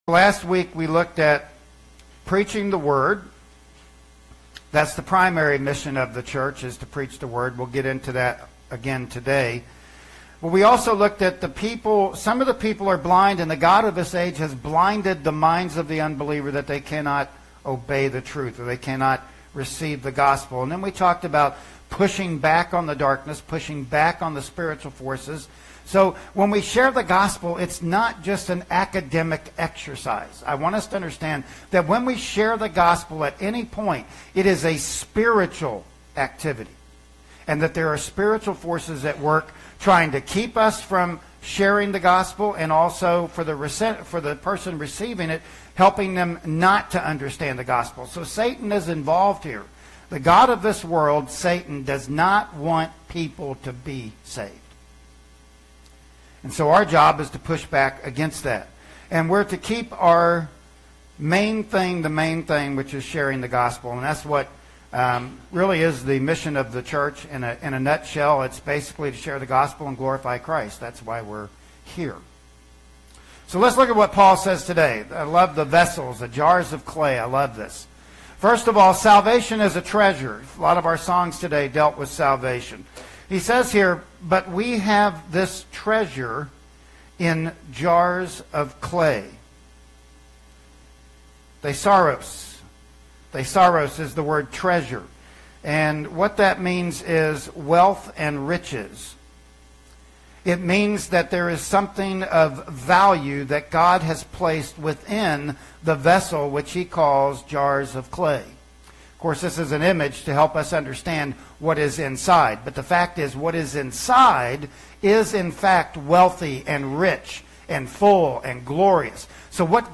Passage: "2 Corinthians 4:7-12" Service Type: Sunday Morning Worship Service